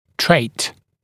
[treɪt] [treɪ][трэйт] [трэй]признак, особенность, характерная черта